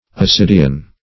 Ascidian \As*cid"i*an\, n. [Gr. ? bladder, pouch.] (Zool.)